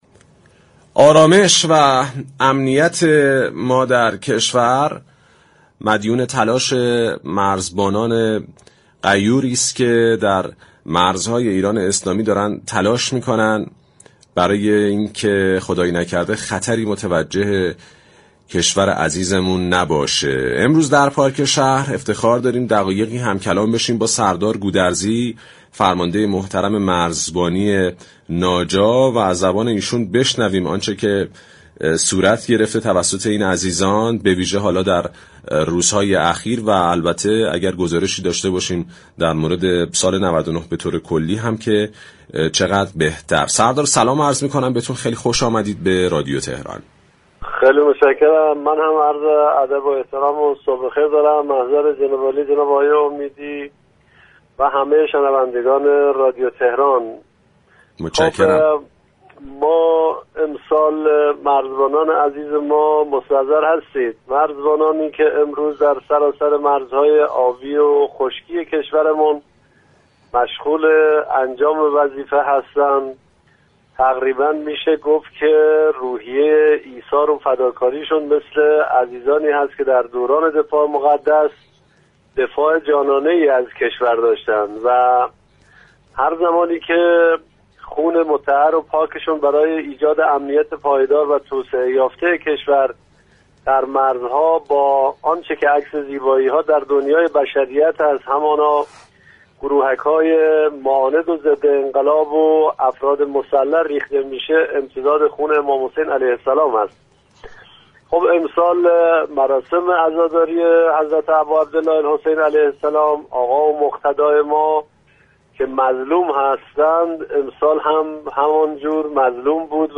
سردار احمدعلی گودرزی، فرمانده مرزبانی ناجا در گفتگو با رادیو تهران اظهار داشت: كشف و ضبط مواد مخدر و مشروبات الكلی نسبت به سال گذشته 72 درصد افزایش داشته است.